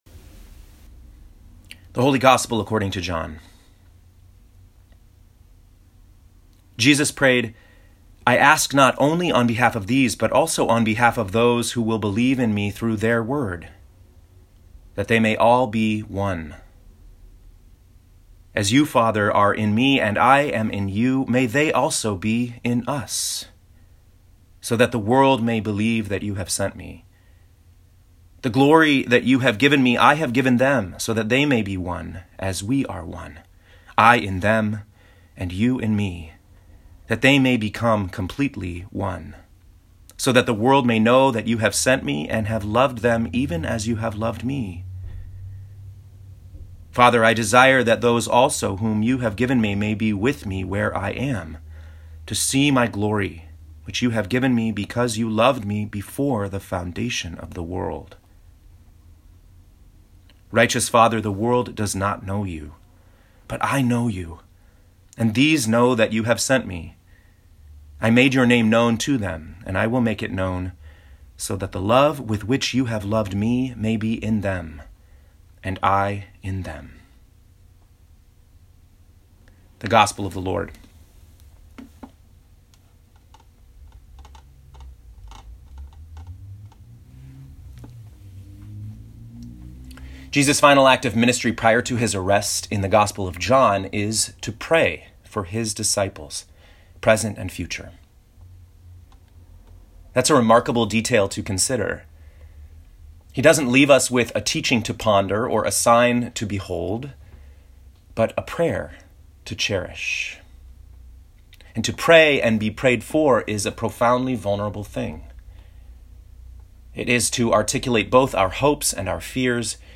Seventh Sunday of Easter, Year C (6/2/2019) Acts 16:16-34 Psalm 97 Revelation 22:12-14, 16-17, 20-21 John 17:20-26 Click the play button to listen to this week’s sermon.